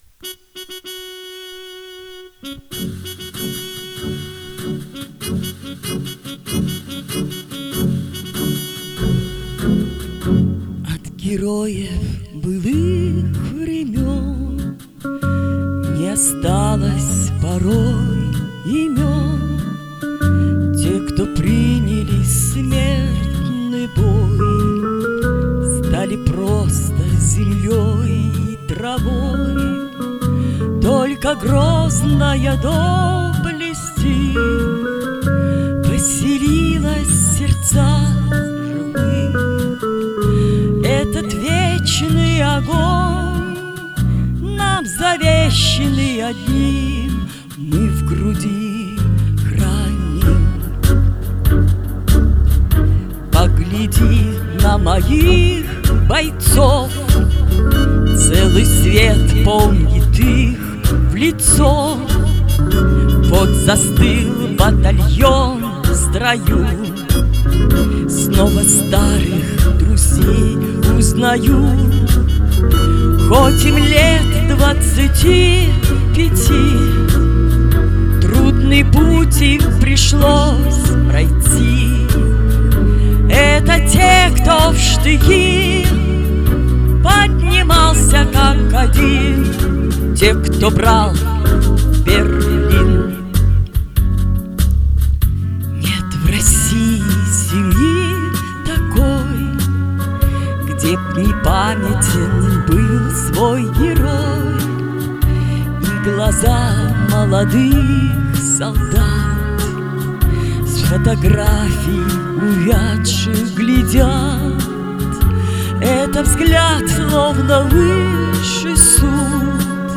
Спели с чувством.